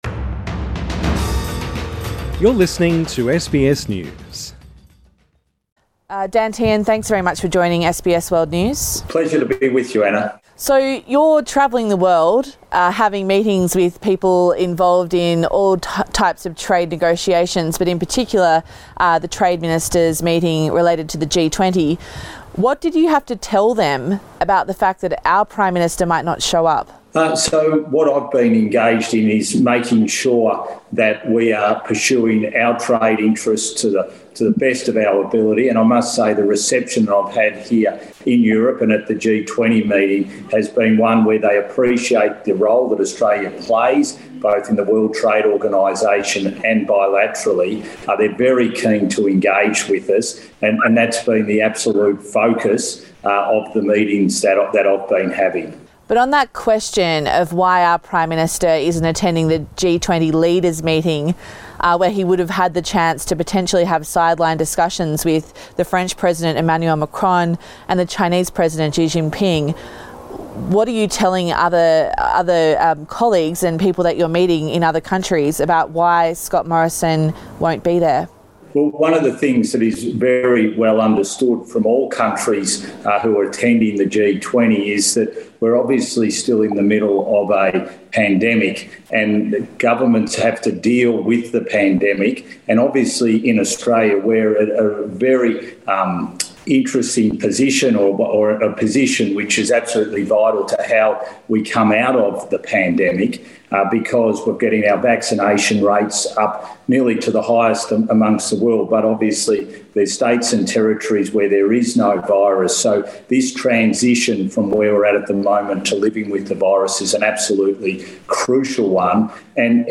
Trade Minister Dan Tehan talks to SBS